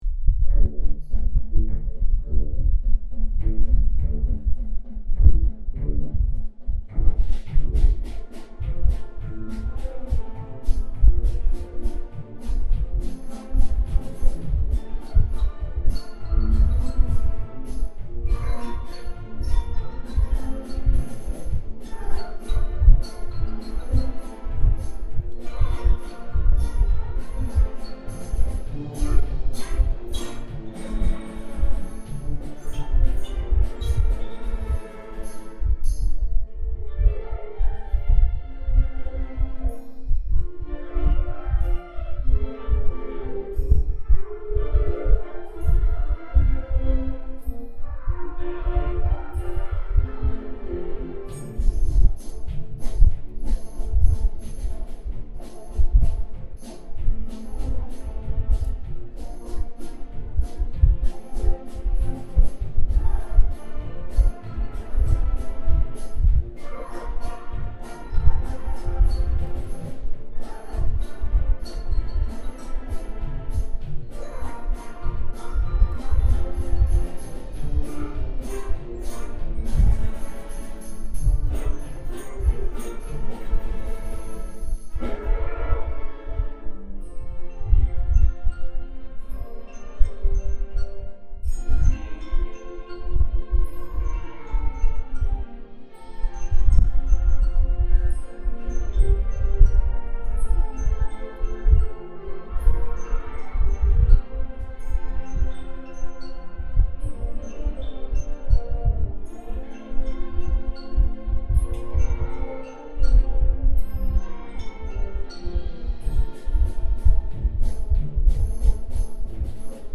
Juan for the Road - Junior Wind